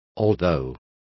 Also find out how aunque is pronounced correctly.